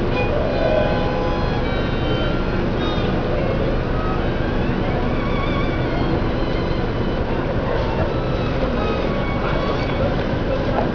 Straßenlärm und Geigenspieler
Musik aus den Geschäften, Gespräche der Menschen, Geräusche von Schuhen auf dem Untergrund und
Das folgende Beispiel zeigt einen Geigenspieler, der sich bemüht, gegen den Lärm und die Hektik zu spielen.
Das entscheidene Muster ist nicht alleine der Klang der Geige, sondern das für dieses Instrument typische Vibrato.
Abb. 01: Auf der Königstraße in Stuttgart spielt jemand Geige.
Die Töne der Geige liegen im oberen Frequenzbereich ab etwa 500 Hz. In der Bildmitte  (bei 5 bis 7 Sec auf der Zeitachse) werden zwei Töne nacheinander sogar mit Vibrato gespielt.